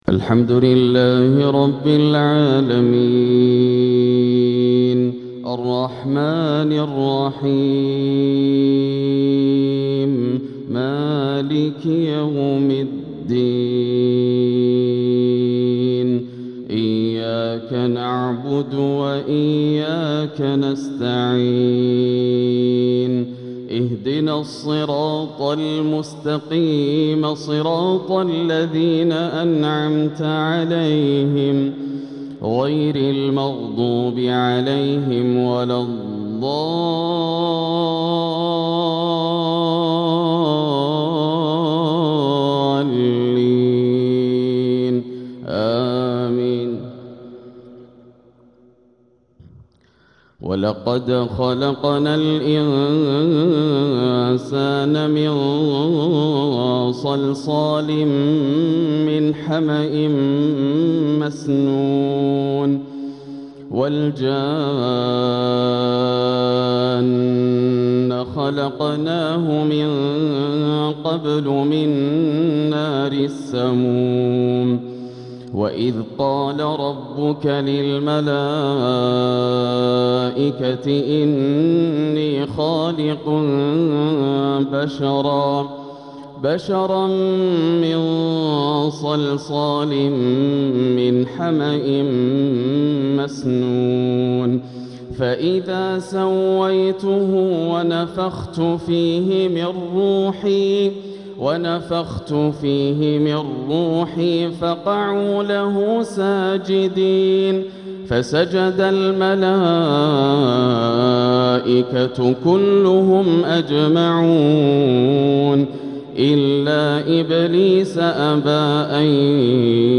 تلاوة من سورة الحجر 26-50 | عشاء الأربعاء 9-4-1447هـ > عام 1447 > الفروض - تلاوات ياسر الدوسري